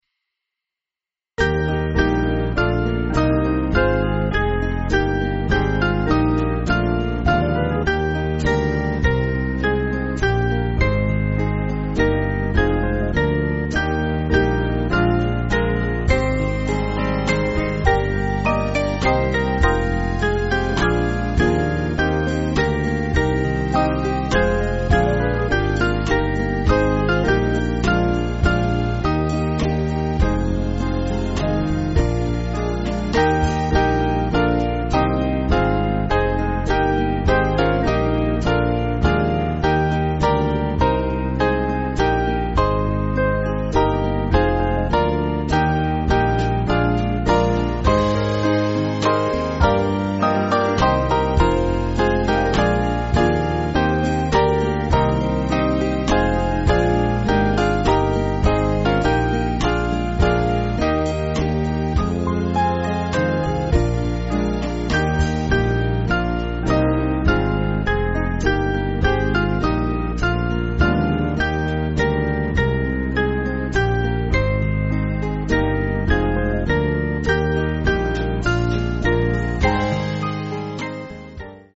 Small Band
(CM)   4/Eb-E